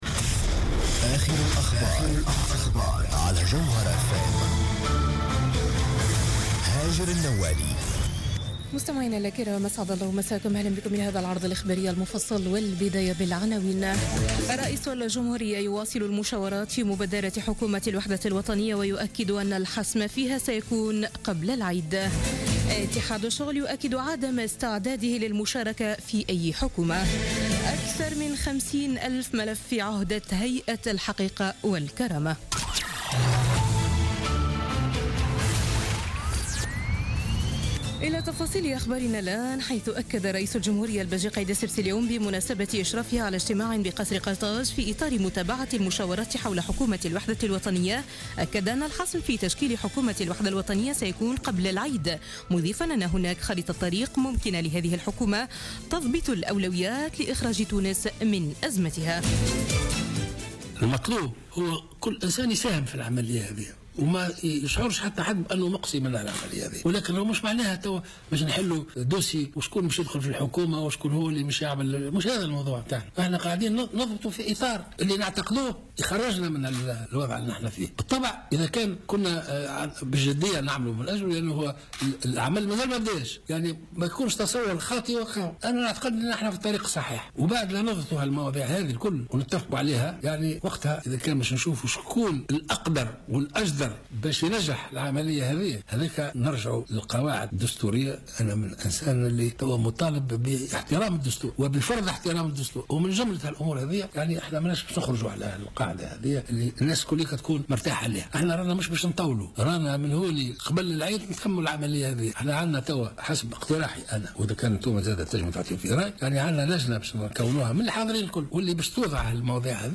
Journal Info 17h00 du mercredi 15 juin 2016